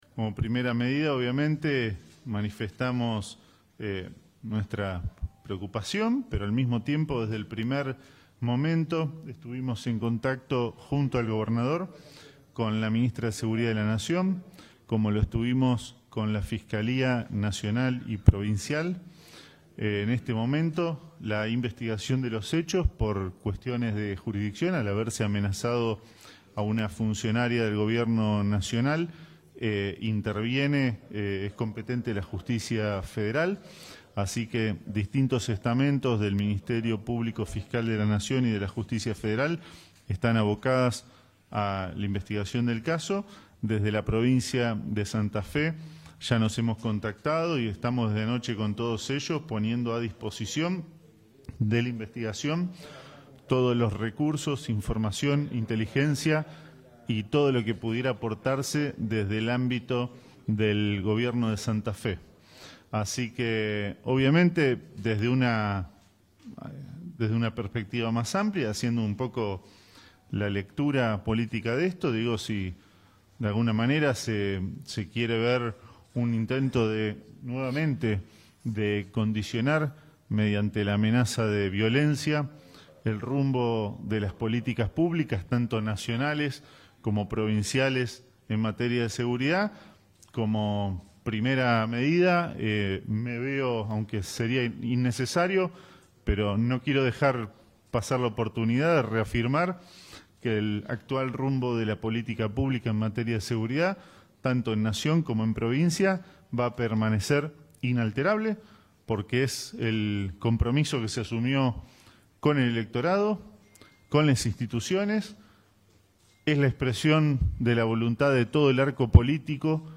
Pablo Cococcioni, ministro de Justicia y Seguridad